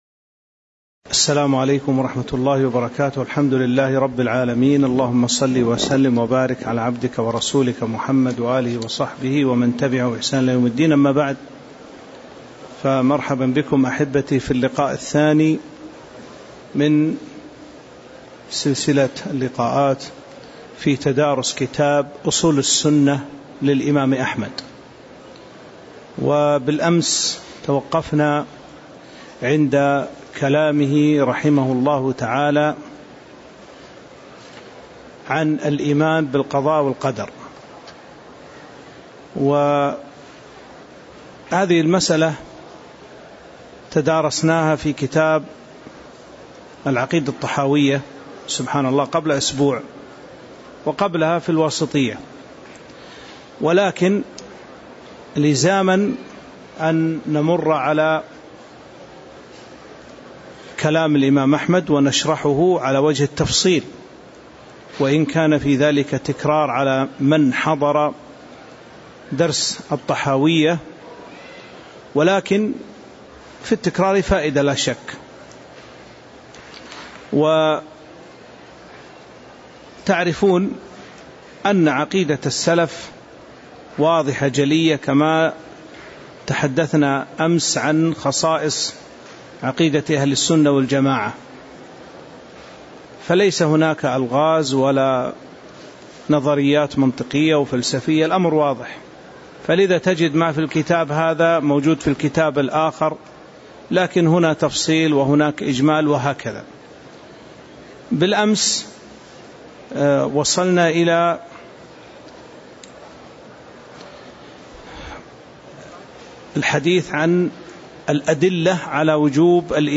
تاريخ النشر ٢٥ صفر ١٤٤٥ هـ المكان: المسجد النبوي الشيخ